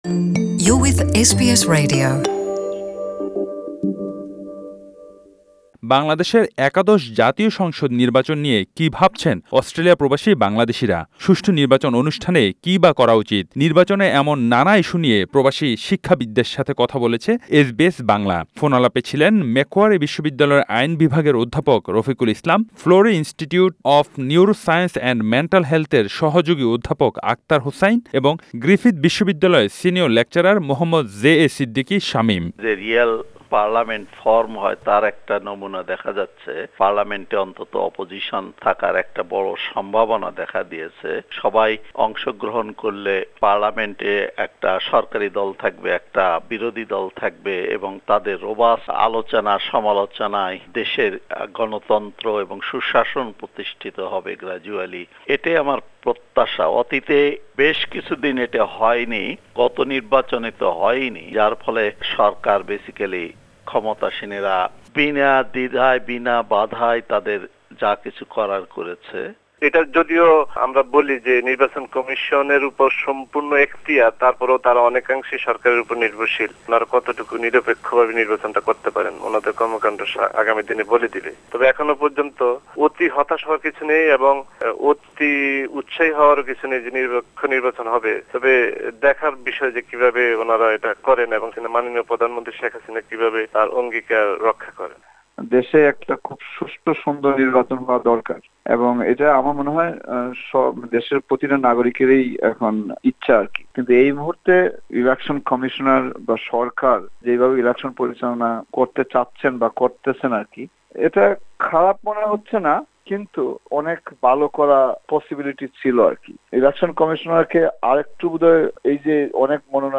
নির্বাচনের এমন নানা ইস্যু নিয়ে প্রবাসী শিক্ষাবিদদের সাথে কথা বলেছে এসবিএস বাংলা।